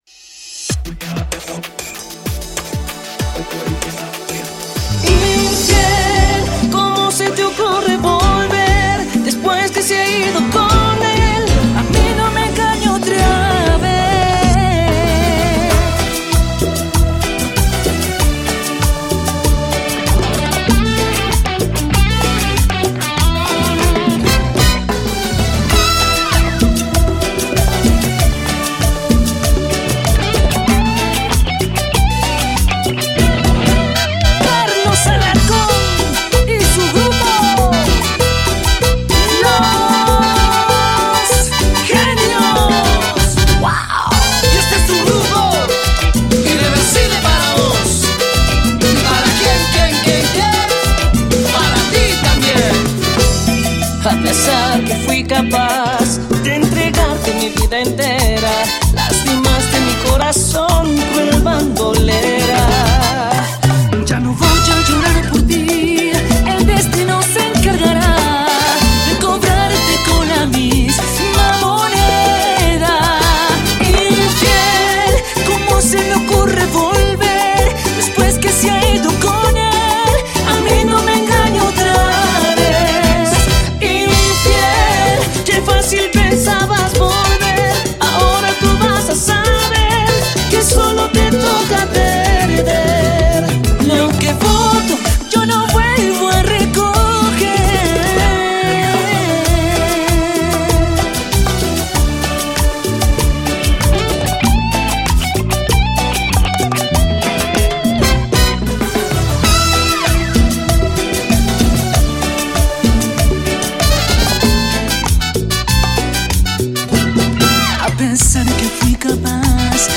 Genre Cumbia Latina